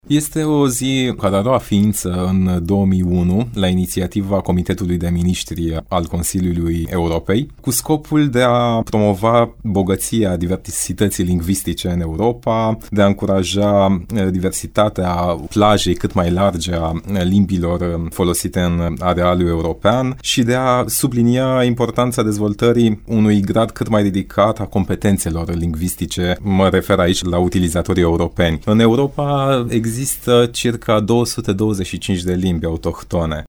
a vorbit într-un interviu